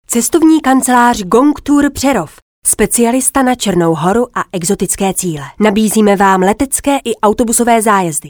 Sprecherin tschechisch (Muttersprache) für Werbung, Voice over, Imagefilm, Industriefilm etc.
Sprechprobe: Industrie (Muttersprache):
Professionell voice over artist from Czech.